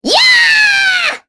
Sonia-Vox_Casting2_kr.wav